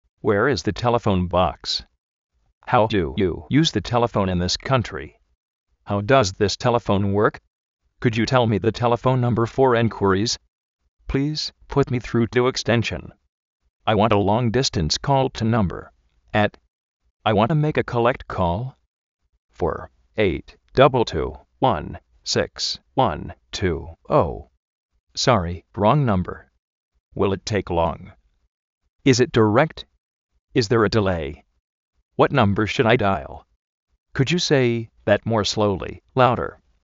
kóin-operéitit péifoun
kárd-operéitit péifoun
érea kóud
fóun kard
risíver, íar-fóun
exténshn
ópereiror